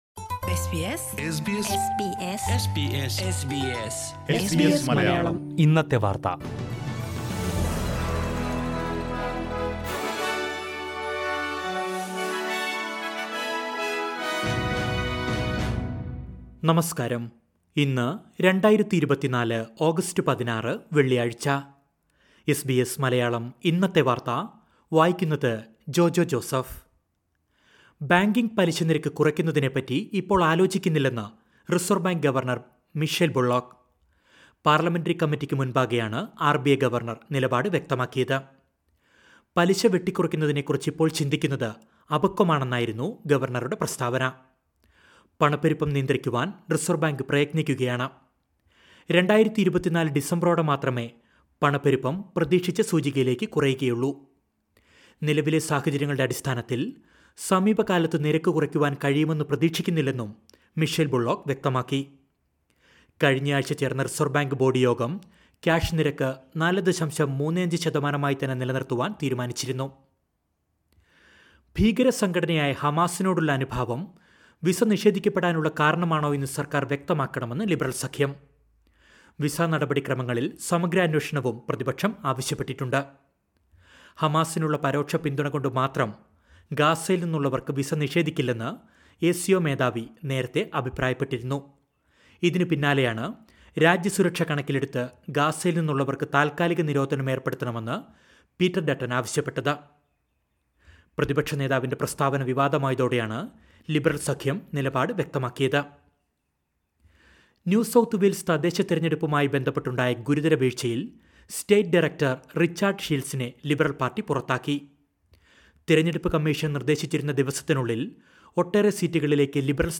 2024 ഓഗസ്റ്റ് 16ലെ ഓസ്‌ട്രേലിയയിലെ ഏറ്റവും പ്രധാന വാര്‍ത്തകള്‍ കേള്‍ക്കാം...